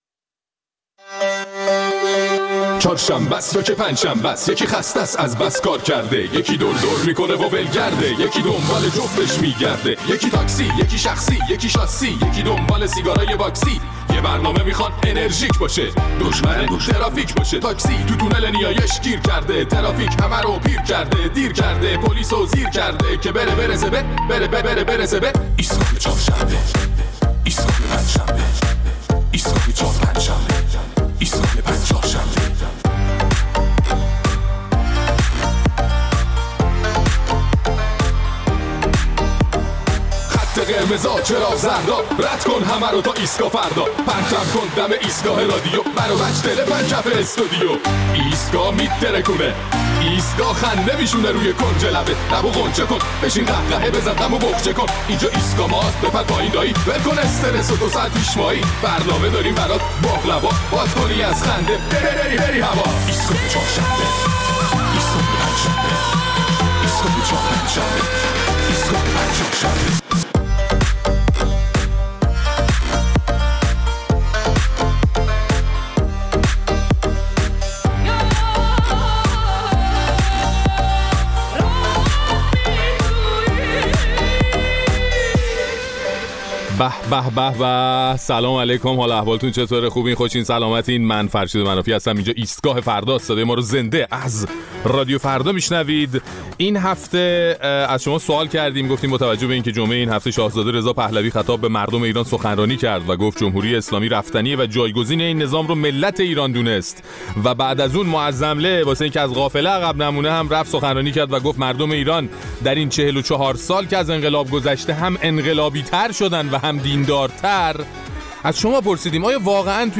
در این برنامه ادامه نظرات شنوندگان ایستگاه فردا را در مورد پیام شاهزاده رضا پهلوی و نظر علی خامنه‌ای که مردم ایران را انقلابی‌تر و دیندارتر از اول انقلاب می‌دانست، می‌شنویم.